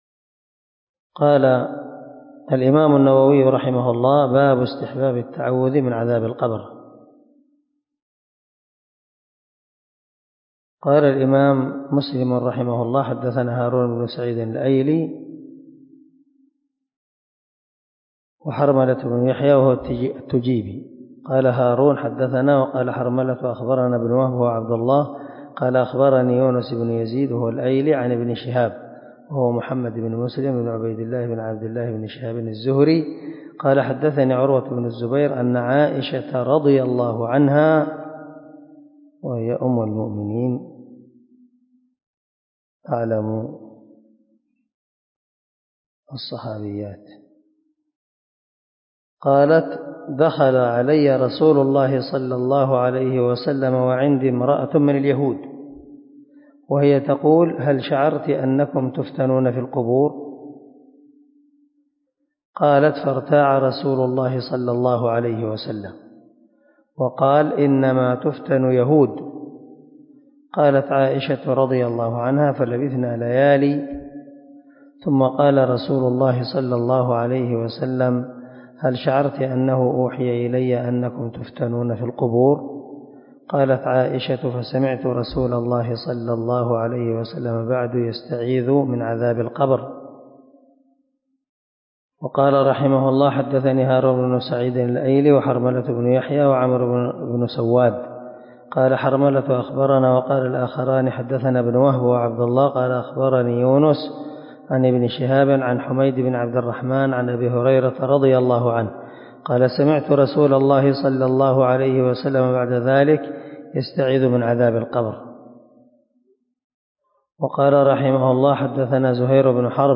371الدرس 43 من شرح كتاب المساجد ومواضع الصلاة حديث رقم (584 - 586 ) من صحيح مسلم
دار الحديث- المَحاوِلة-